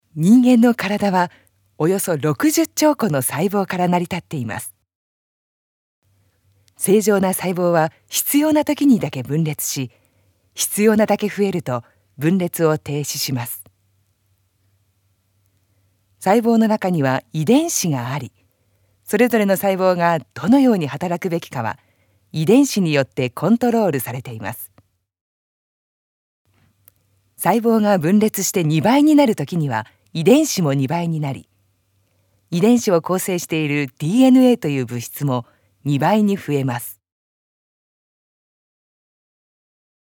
音声サンプル
声は明るく安心感を持てる音色で、伝わりやすく聞き心地のよいアナウンスを心掛けています。